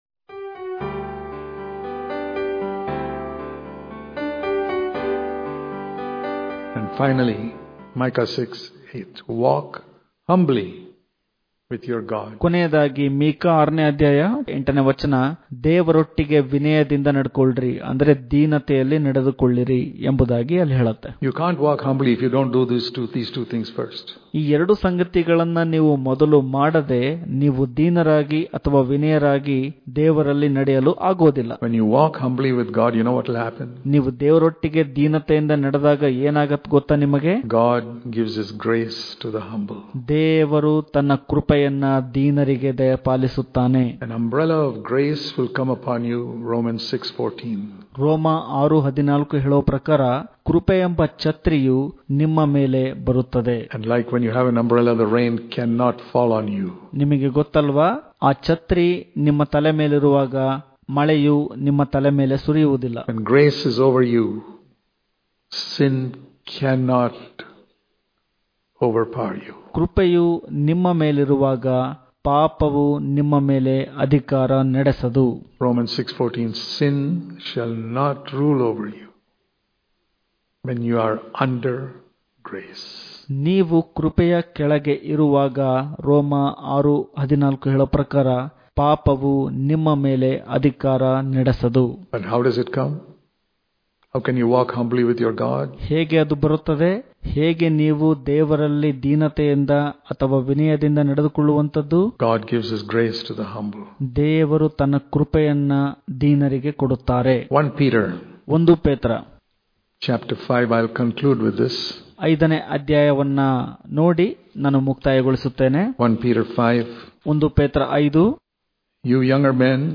ಇಂದಿನ ಧ್ಯಾನ
Daily Devotions